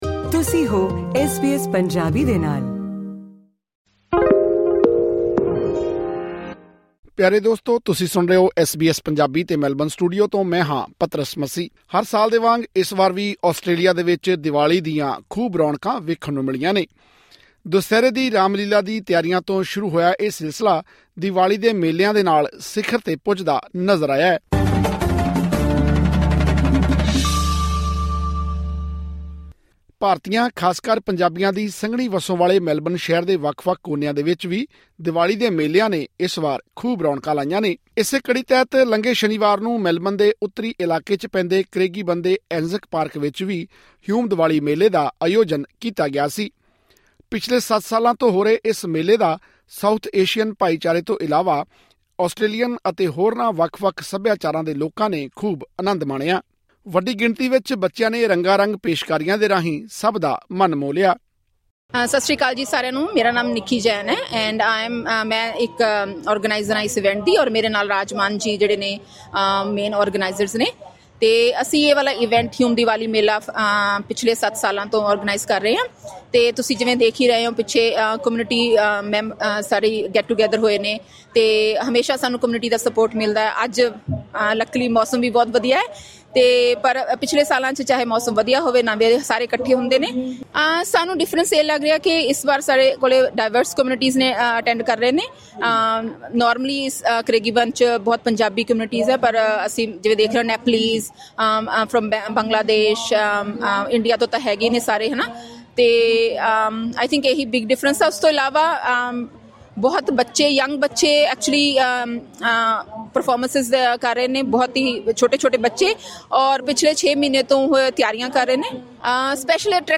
ਇਸ ਦੀਵਾਲੀ ਮੇਲੇ ਦੀਆਂ ਰੌਣਕਾਂ ਦਾ ਹਿੱਸਾ ਬਣਨ ਲਈ ਸੁਣੋ ਇਹ ਆਡੀਓ ਰਿਪੋਰਟ 🔊 ਸਾਡੇ ਸਾਰੇ ਪੌਡਕਾਸਟ ਇਸ ਲਿੰਕ ਰਾਹੀਂ ਸੁਣੇ ਜਾ ਸਕਦੇ ਹਨ।